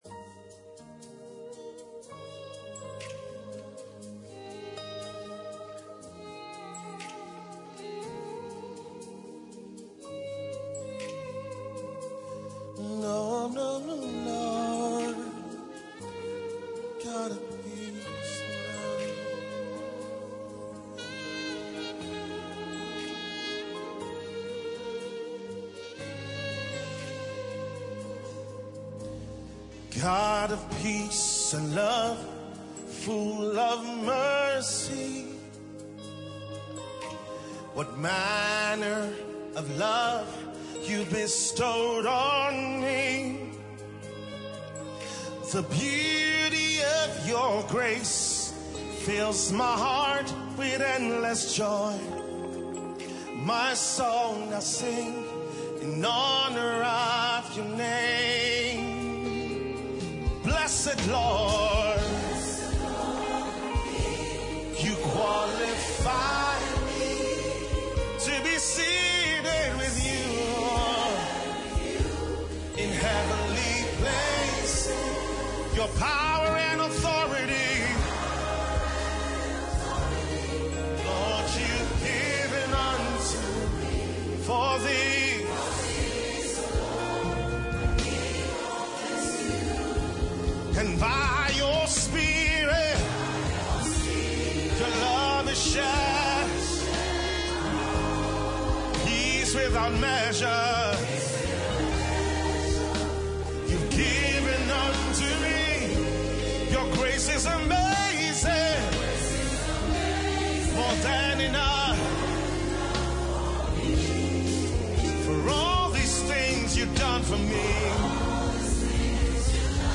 Key – A flat